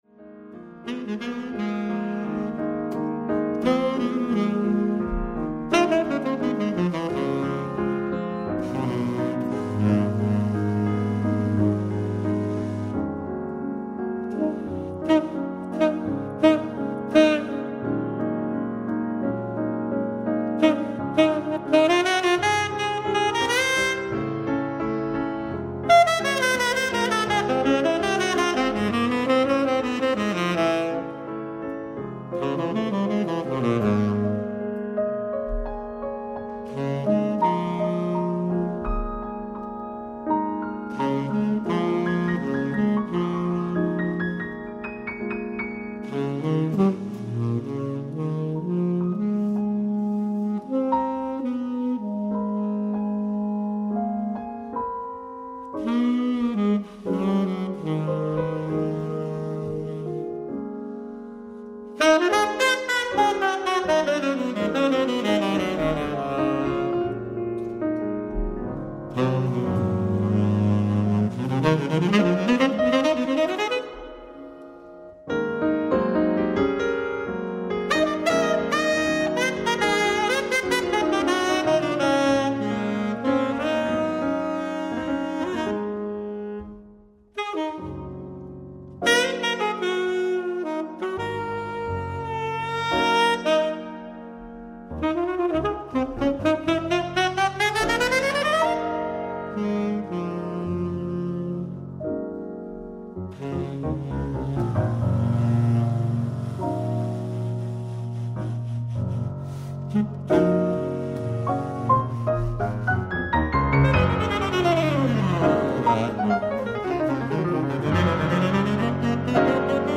ライブ・アット・キュリー・ジャズフェスティバル、キュリー、スイス 04/08/2025
オフィシャル級のデジタル放送音源！！
※試聴用に実際より音質を落としています。